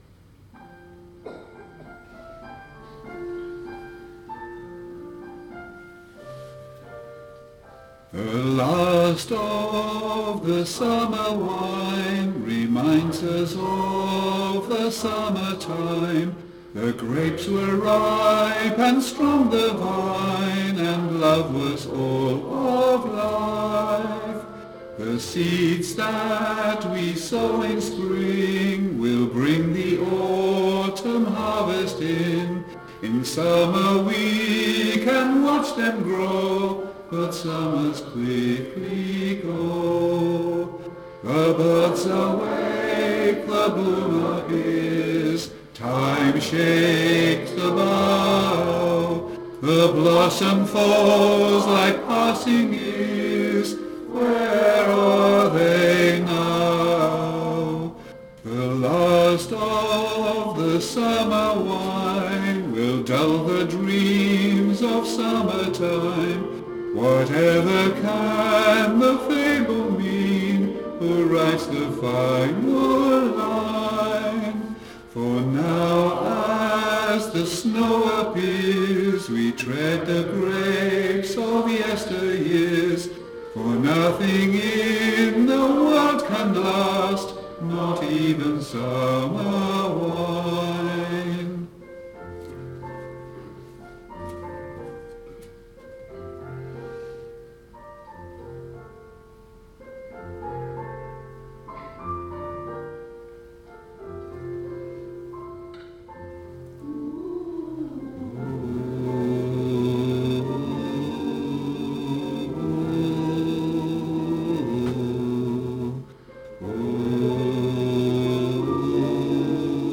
Learning Track Test - Reading Male Voice Choir
Title Tenor 1 Tenor 2 Baritone Bass Base Track